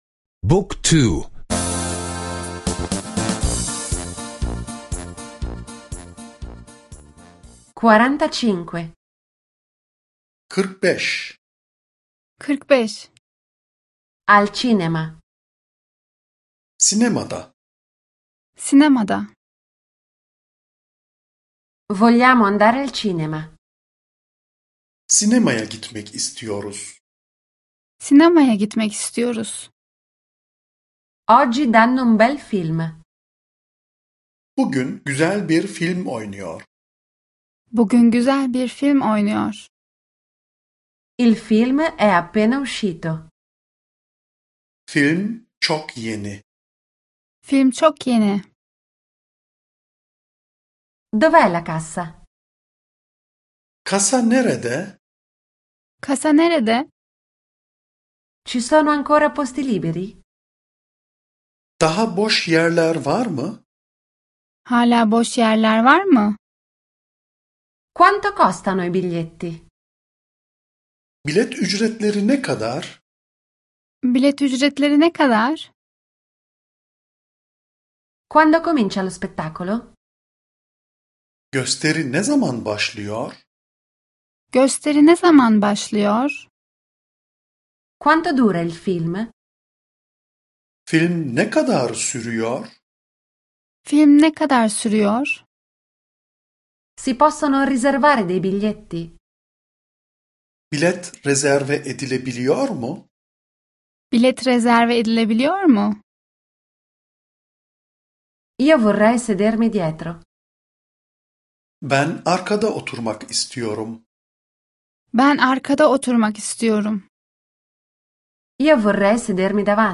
Audio corso turco — ascolta online